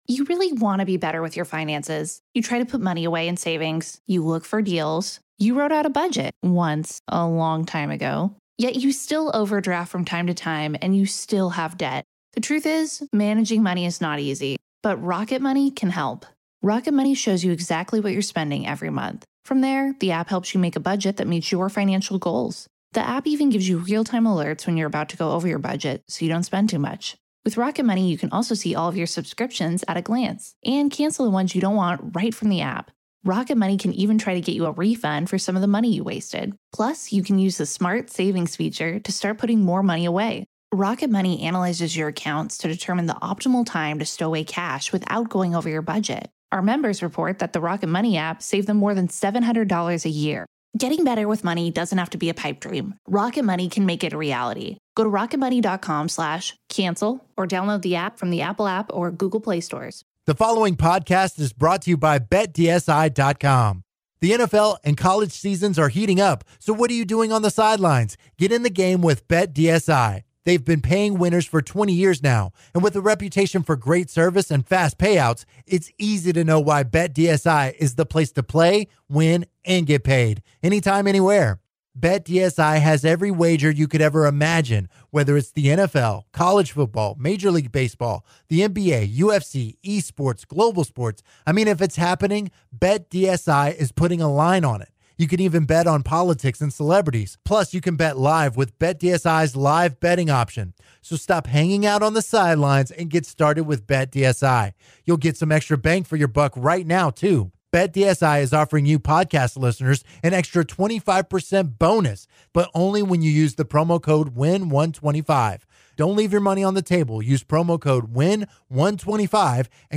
The guys are on location in Angleton and they get the first hour of the show going by recapping the Rockets beating of the Warriors last night in Houston.